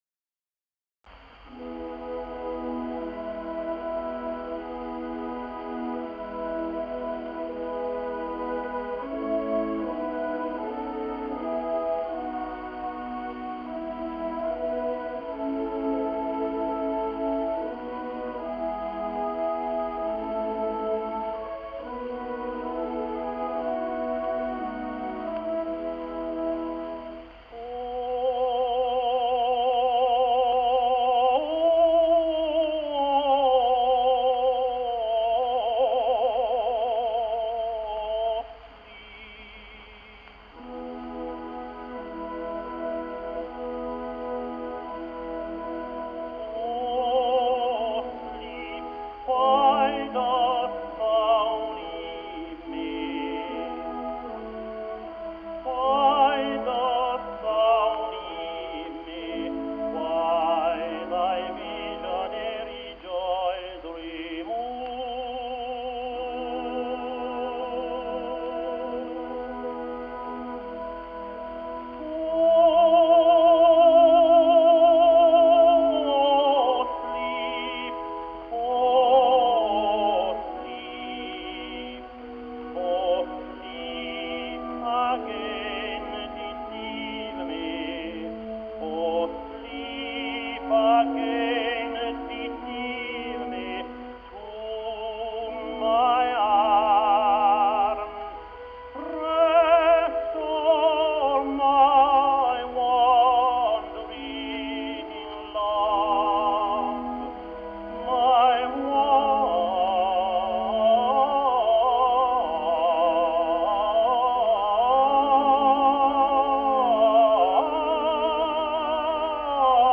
John McCormack | Irish Tenor | 1884 - 1945 | History of the Tenor